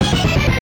jingles-hit_04.ogg